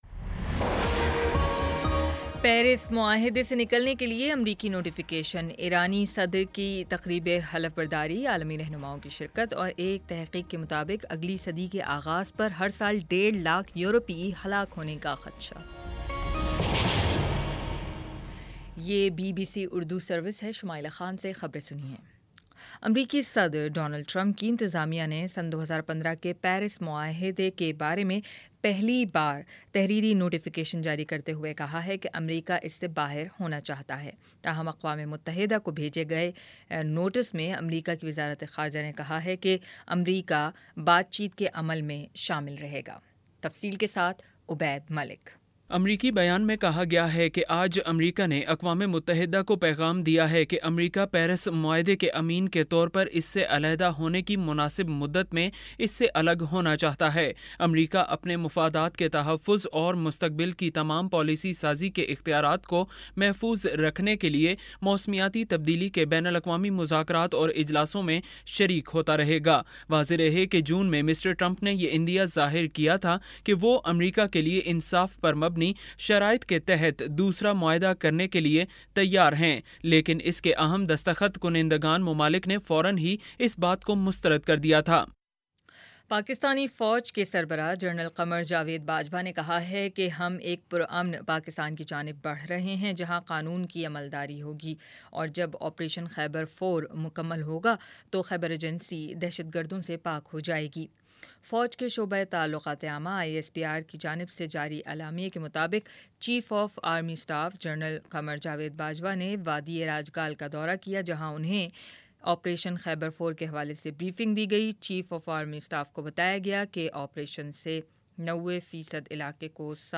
اگست 05 : شام چھ بجے کا نیوز بُلیٹن